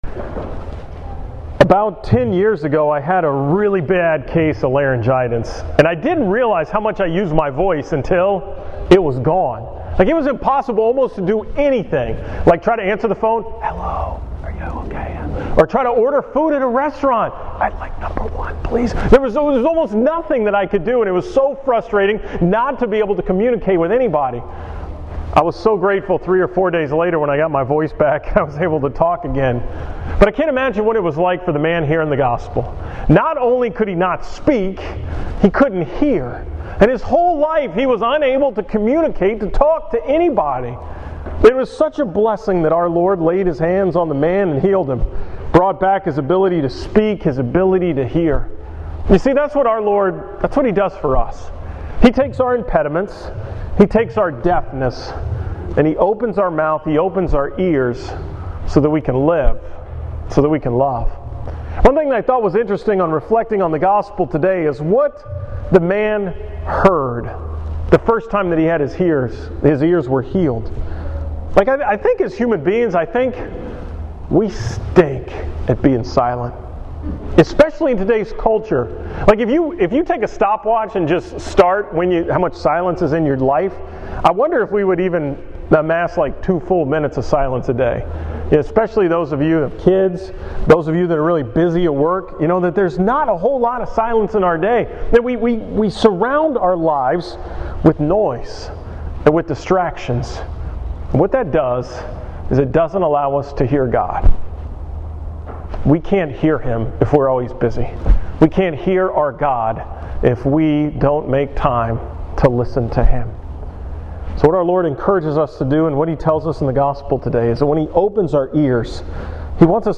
From Mass on Sunday, September 6th (23rd Sunday in Ordinary Time)